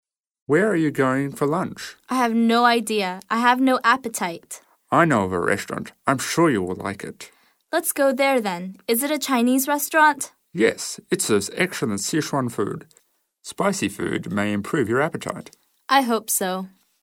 英语口语900句 02.02.对话.2.吃午餐 听力文件下载—在线英语听力室